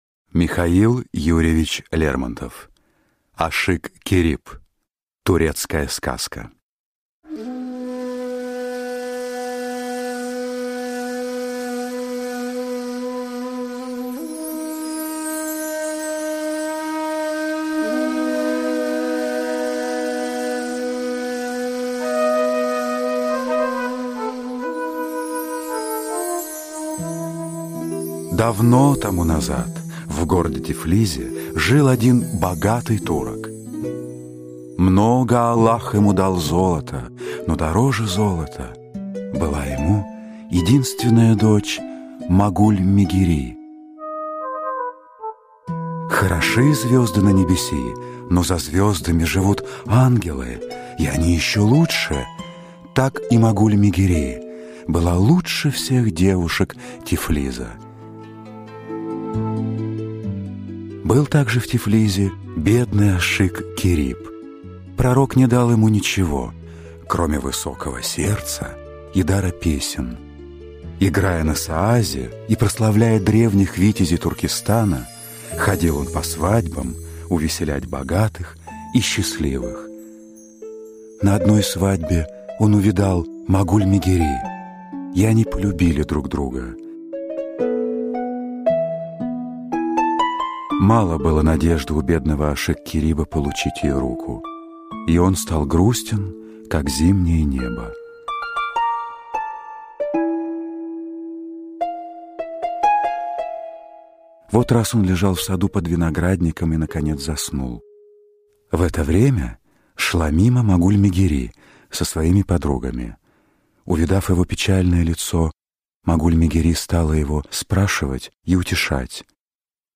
Ашик-Кериб — слушать аудиосказку Михаил Лермонтов бесплатно онлайн